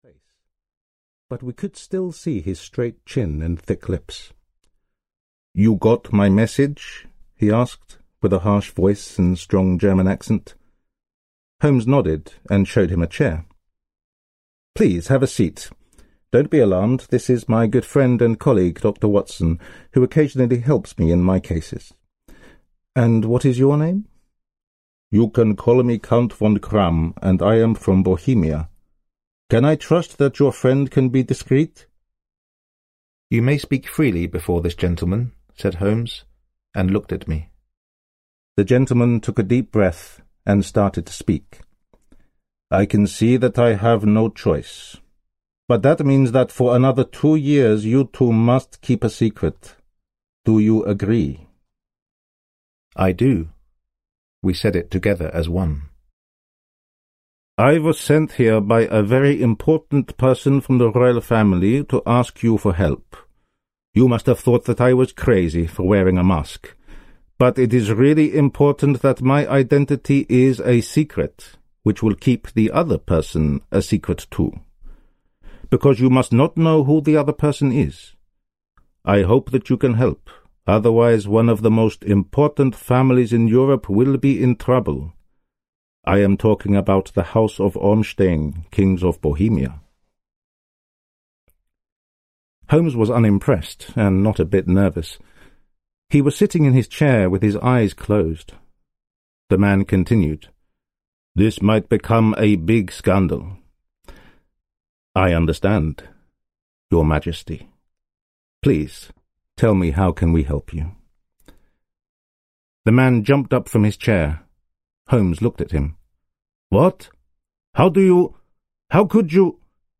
Audio kniha
Ukázka z knihy
Audiokniha je načtená rodilým mluvčím.